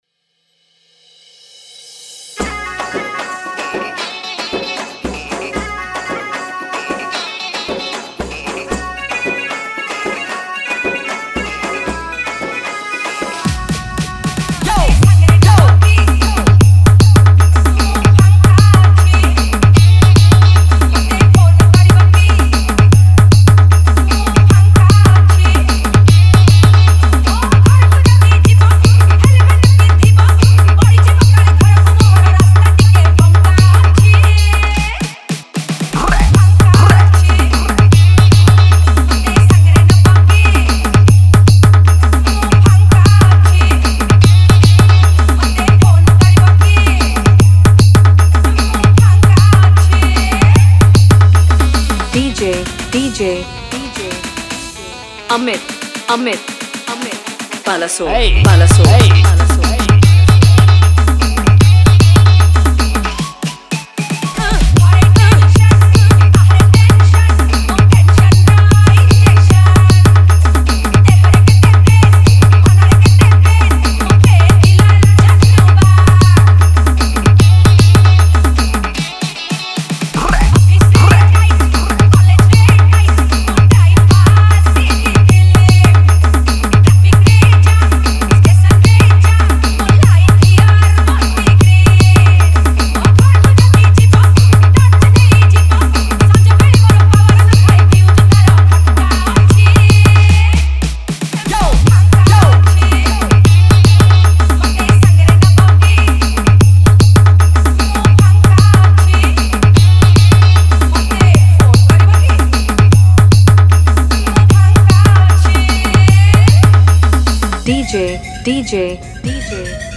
Category: Holi Special Odia Dj Remix Songs